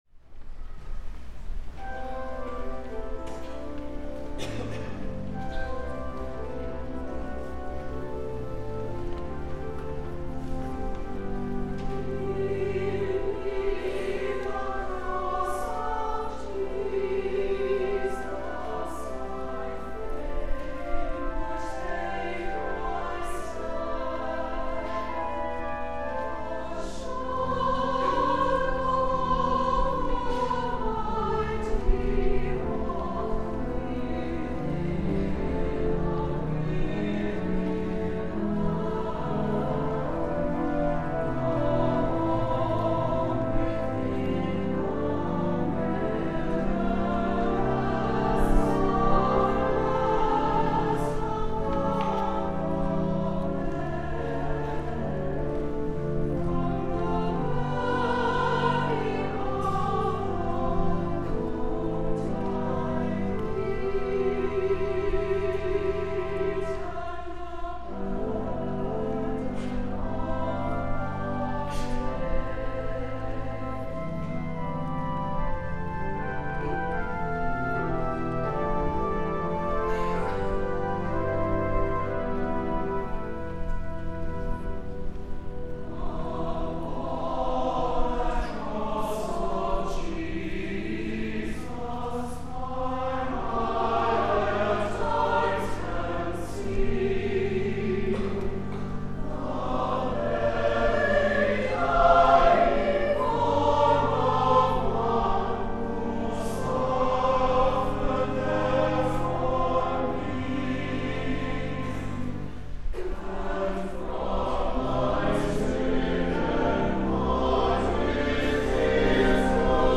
Arranging A Wonderful Old Hymn for Church Choir
organ
My contributions consist mostly of providing fresh harmonies and rich accompaniment so that a new pacing moves the listener to a point of deeper contemplation.  I came up with the introduction, interludes, and ending.  The middle verse retains the original rhythm, but I felt it best to alter the melody line to be almost unrecognizeable so that it more effectively tracks the plaintive text.